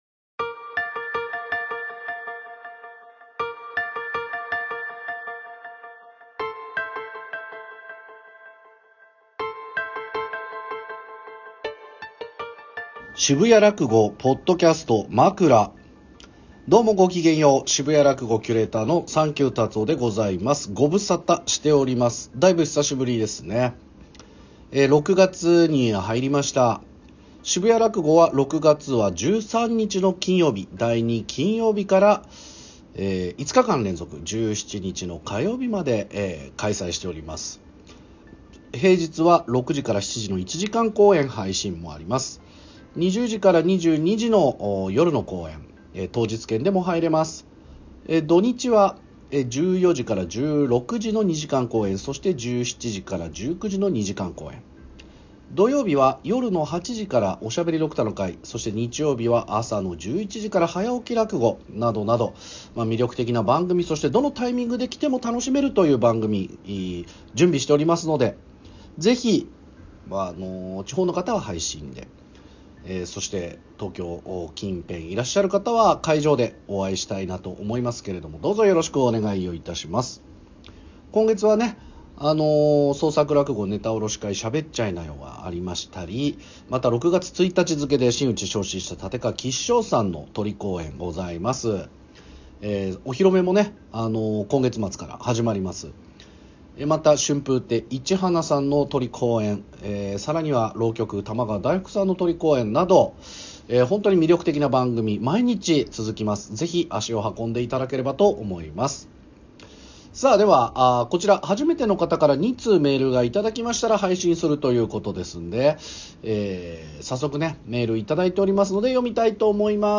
渋谷らくごのポッドキャスト渋谷のユーロライブで行われている「渋谷らくご」。そこで収録された「まくら」を配信中です。